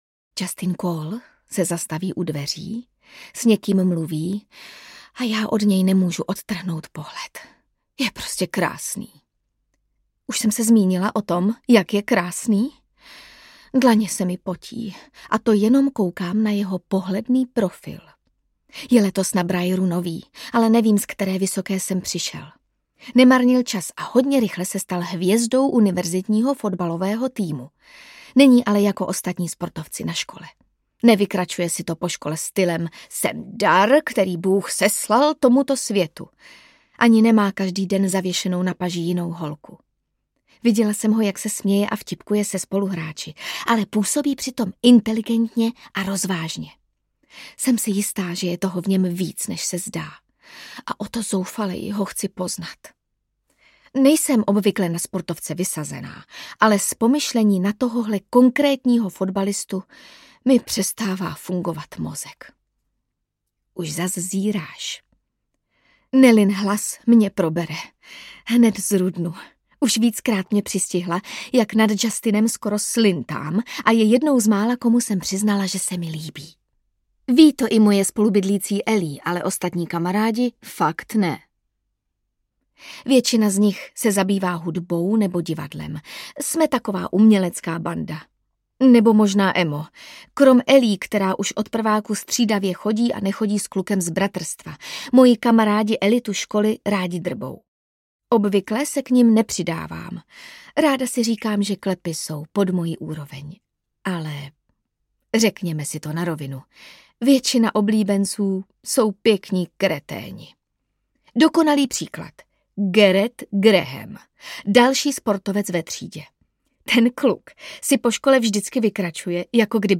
Návrh audiokniha
Ukázka z knihy
| Vyrobilo studio Soundguru.